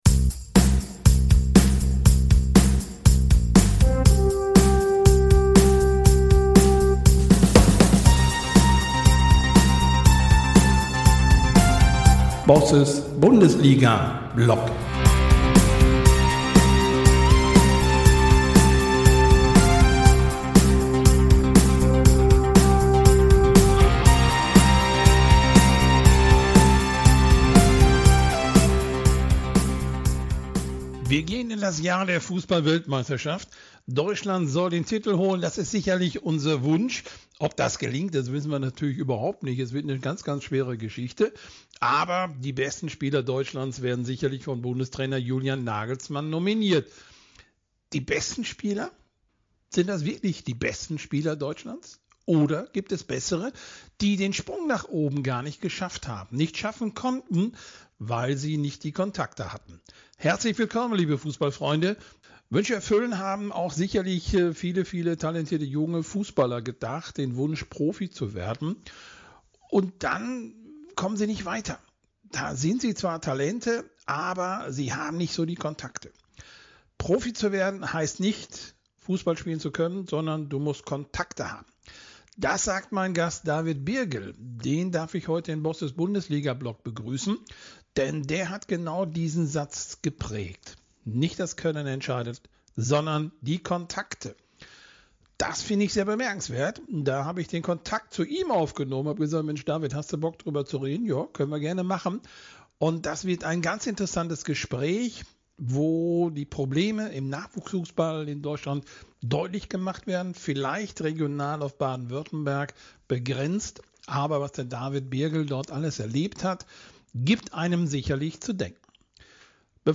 Wir sprechen offen über die Strukturen im deutschen Fußball, verpasste Chancen und die Rolle von Beratern.